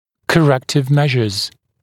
[kə’rektɪv ‘meʒəz][кэ’рэктив ‘мэжэз]корректирующие меры